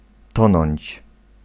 Ääntäminen
IPA : /draʊn/ US : IPA : [draʊn]